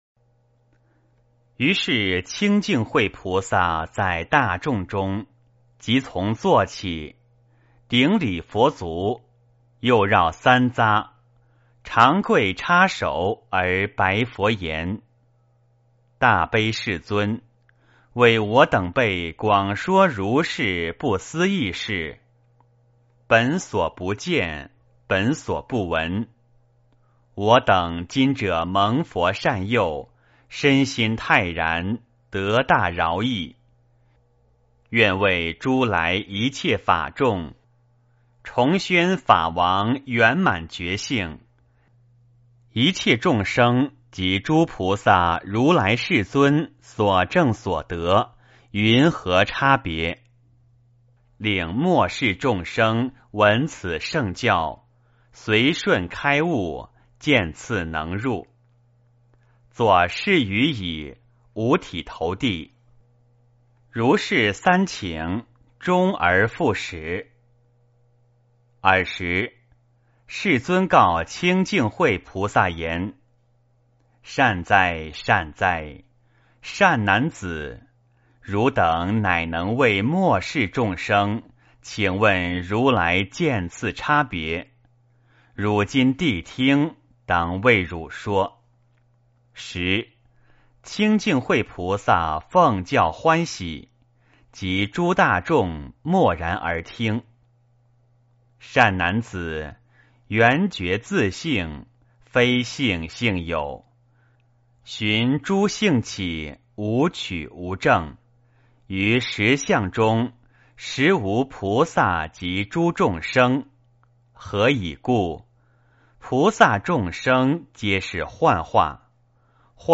圆觉经-06清净慧菩萨 - 诵经 - 云佛论坛
圆觉经-06清净慧菩萨 诵经 圆觉经-06清净慧菩萨--未知 点我： 标签: 佛音 诵经 佛教音乐 返回列表 上一篇： 圆觉经-04金刚藏菩萨 下一篇： 圆觉经-10普觉菩萨 相关文章 准提祈愿之歌--海涛法师 准提祈愿之歌--海涛法师...